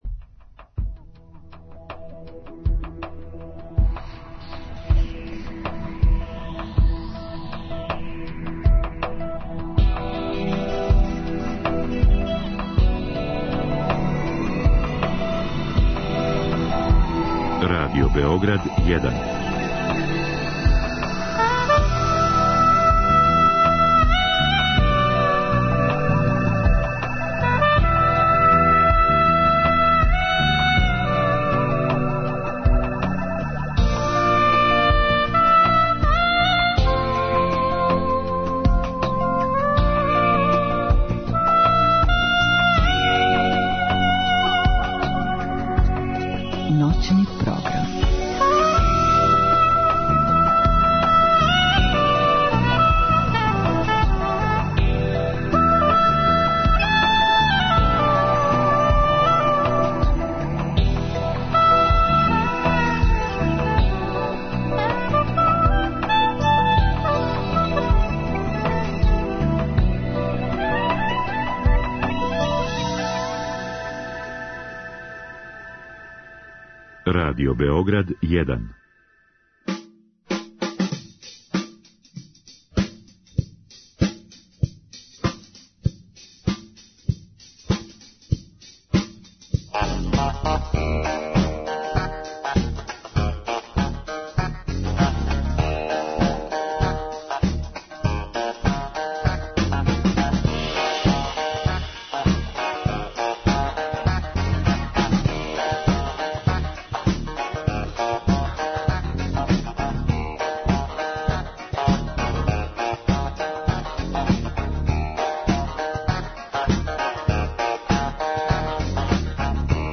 Спој радија, интернета и живе музике. Да ли је интернет равноправан медиј са телевизијиом и радијом или је можда преузео медијски примат?
Наравно свираће уживо. sHpiritus mOvens вечерас су